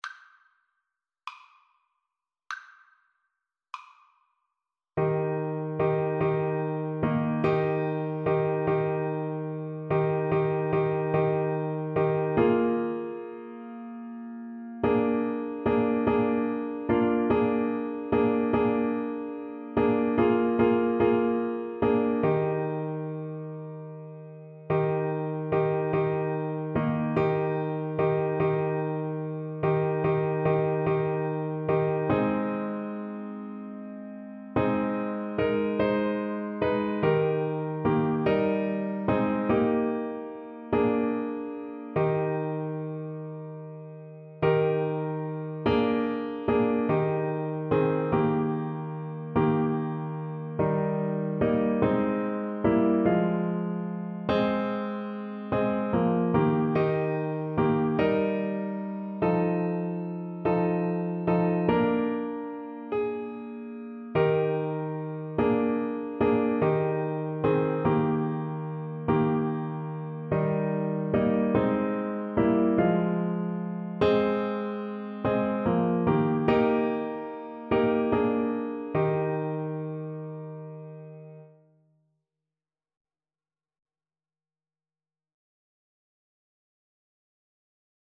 6/8 (View more 6/8 Music)
Soprano Saxophone  (View more Easy Soprano Saxophone Music)
Classical (View more Classical Soprano Saxophone Music)